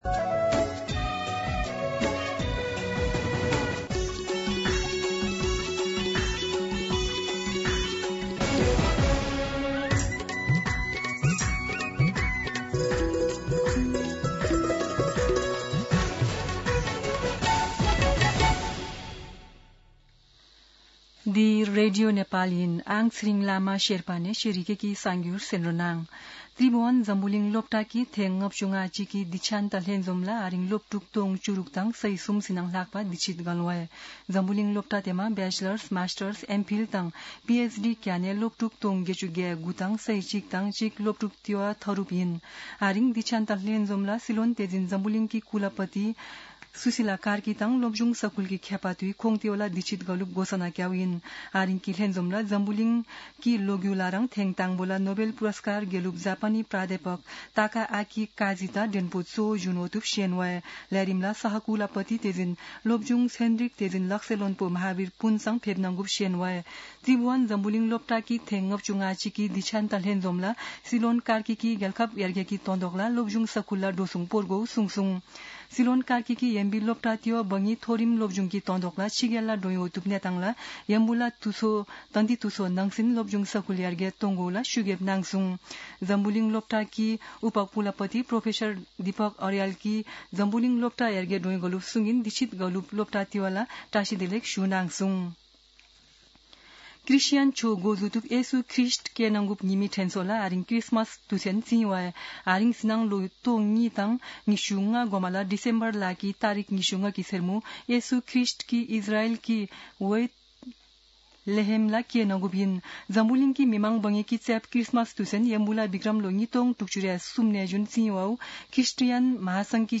शेर्पा भाषाको समाचार : १० पुष , २०८२
Sherpa-News-2.mp3